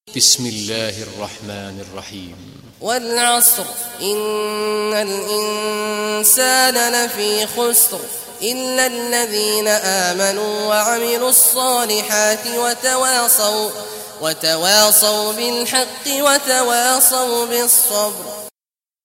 Surah Asr Recitation by Sheikh Awad al Juhany
Surah Asr, listen or play online mp3 tilawat / recitation in Arabic in the beautiful voice of Sheikh Abdullah Awad al Juhany.